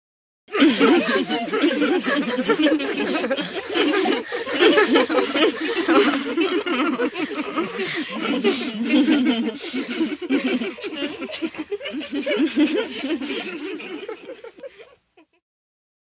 HUMAN VOICES & SOUNDS TEENAGE GIRLS: INT: Giggling with mouths closed, constant.